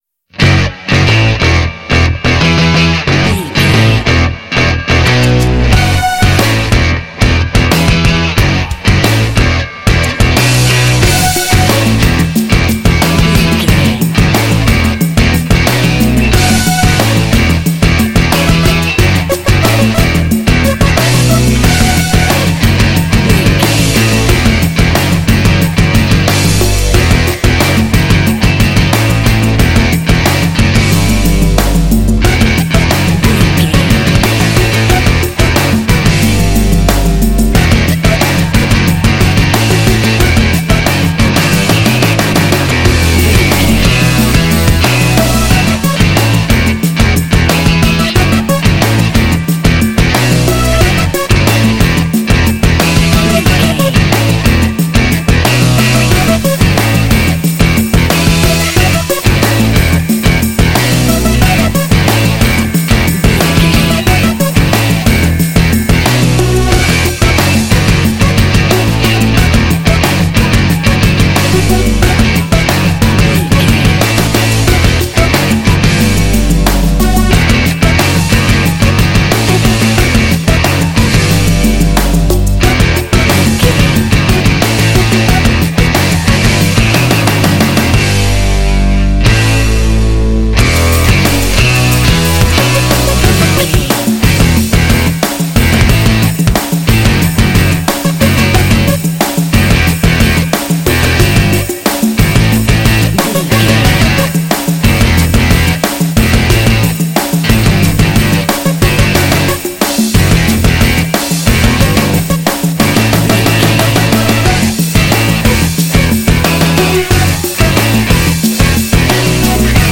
Epic / Action
Phrygian
F#
aggressive
electric guitar
bass guitar
drums
synthesiser
rock
heavy metal
punk rock